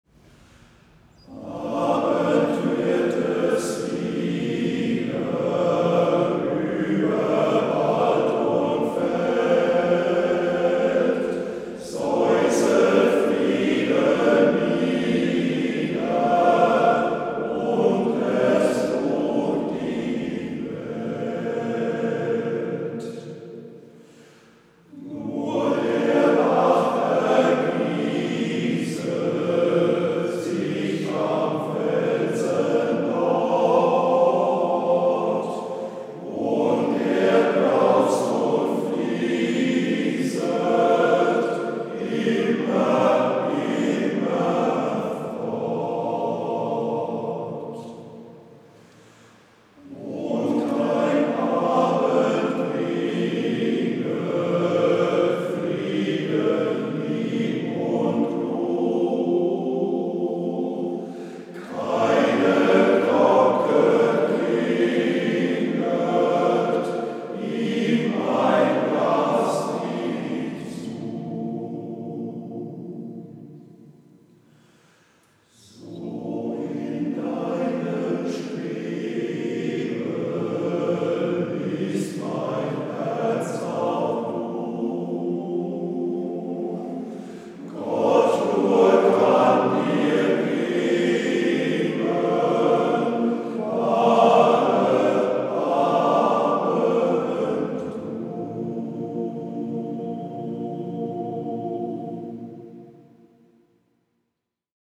Chormusik
Aufnahme am 16.12.2015 (Bewerbungsstück Deutsches Chorfest Stuttgart)